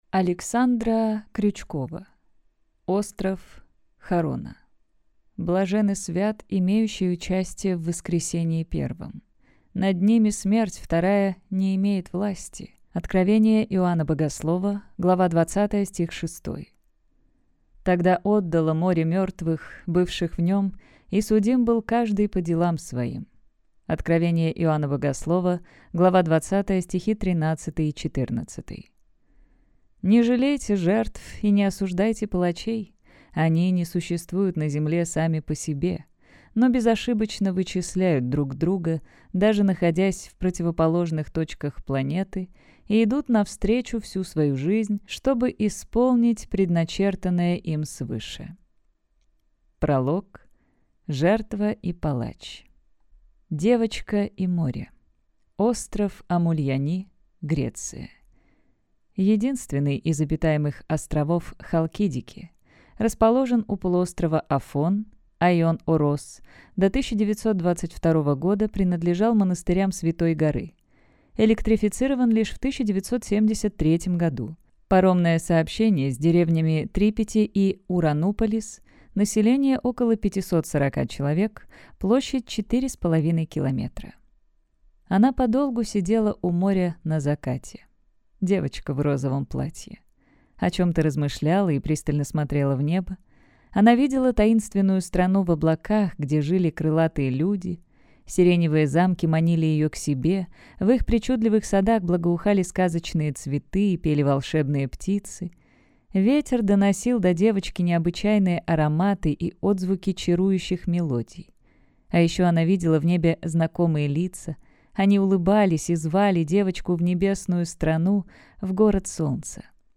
Аудиокнига Остров Харона.
Прослушать и бесплатно скачать фрагмент аудиокниги